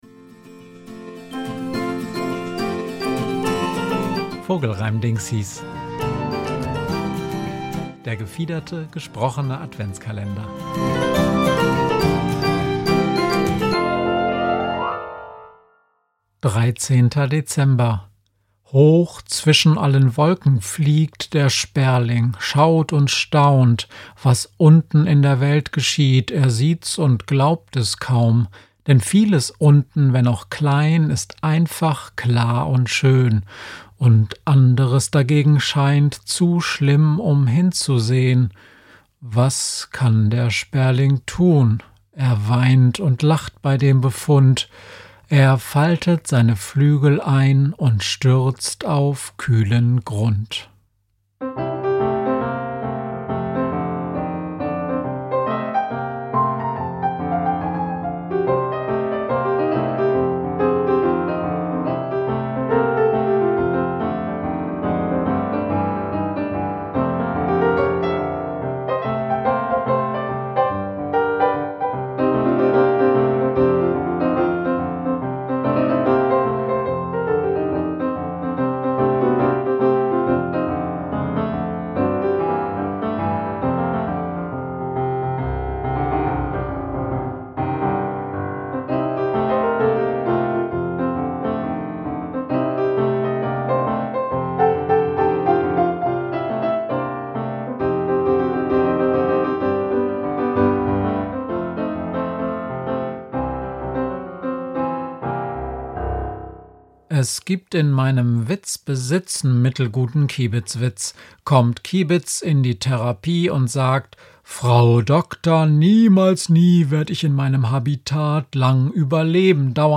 Vogelreimdingsis ist der gefiederte, gesprochene Adventskalender